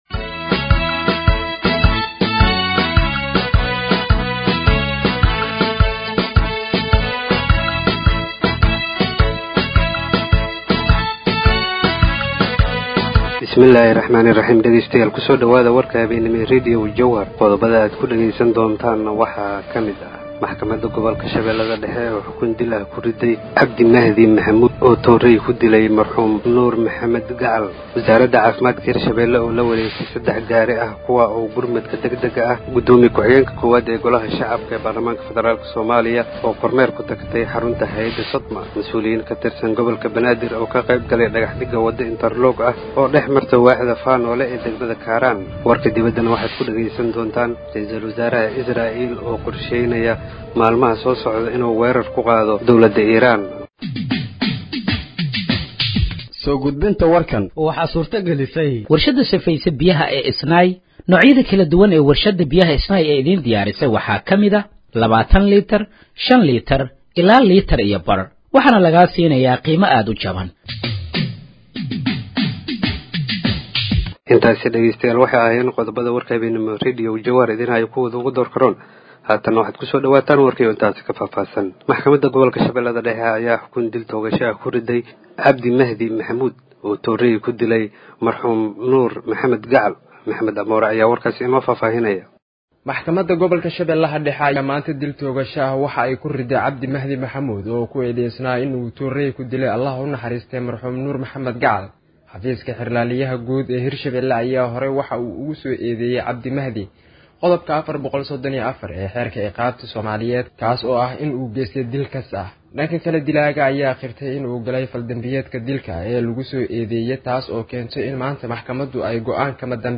Dhageeyso Warka Habeenimo ee Radiojowhar 29/05/2025
Halkaan Hoose ka Dhageeyso Warka Habeenimo ee Radiojowhar